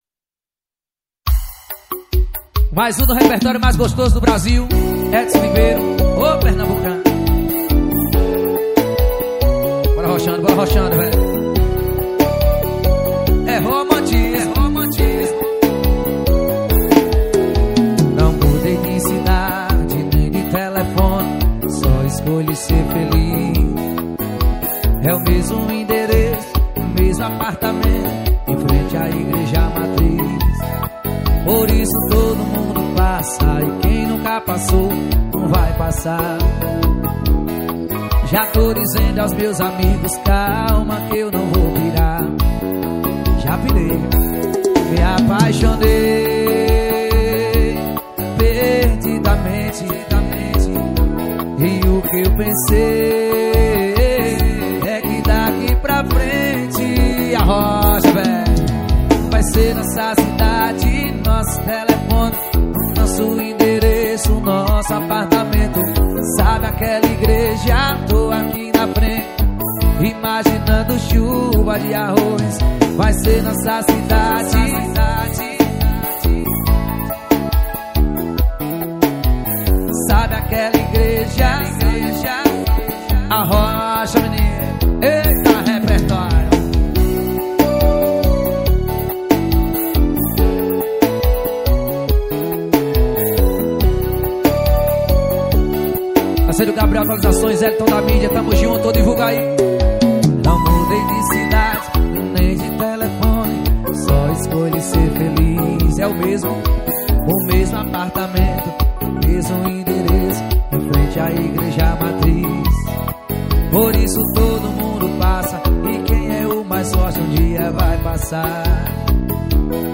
2024-12-23 19:00:56 Gênero: Arrocha Views